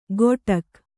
♪ goṭak